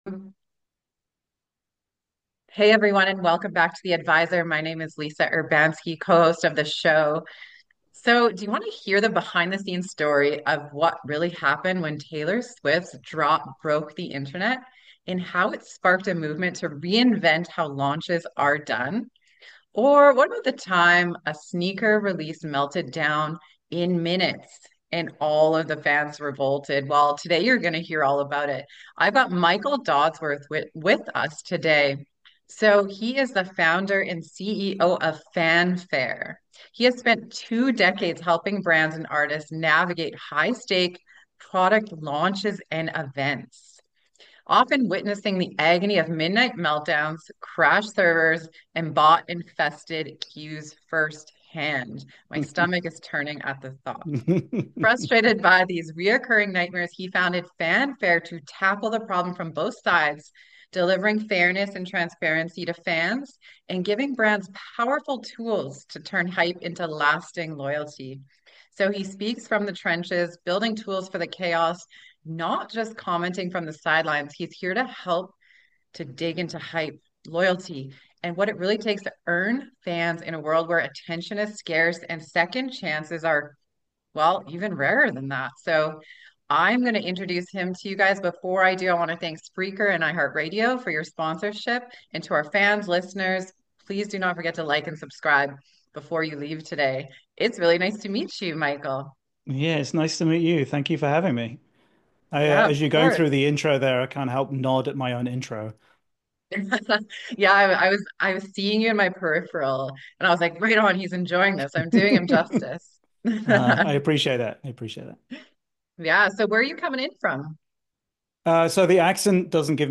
👉 Love insightful and life-changing interviews?